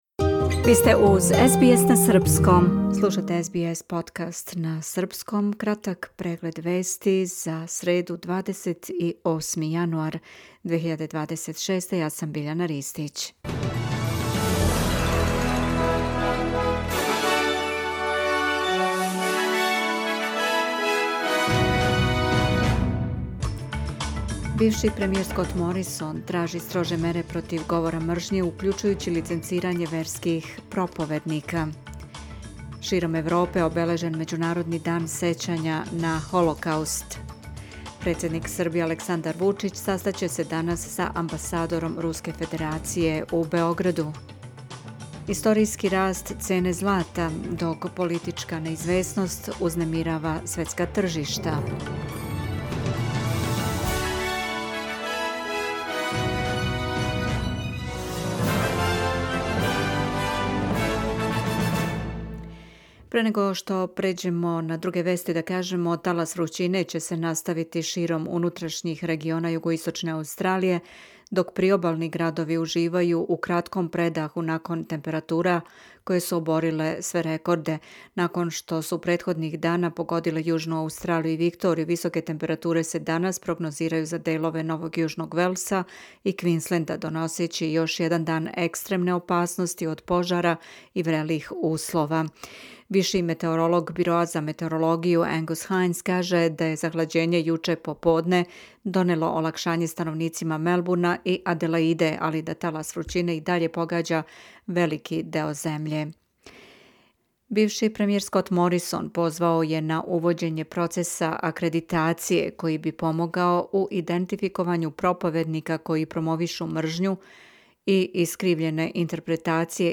Serbian News Bulletin